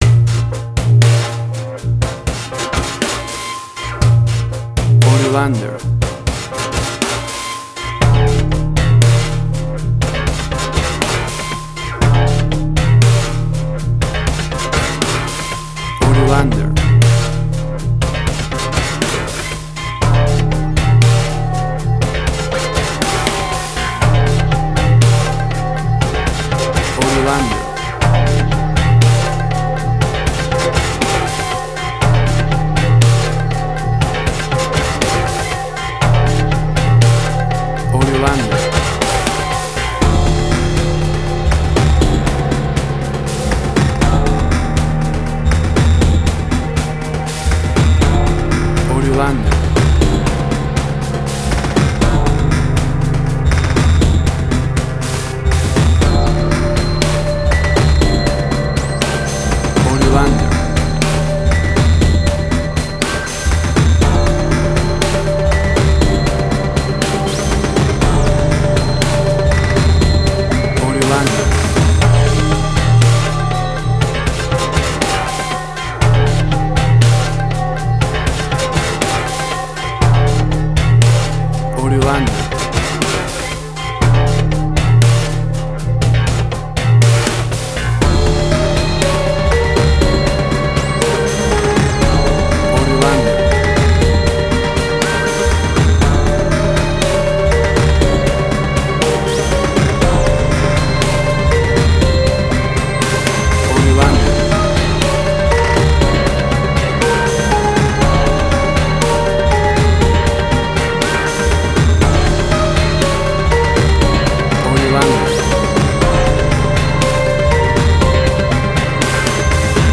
Suspense, Drama, Quirky, Emotional.
Tempo (BPM): 60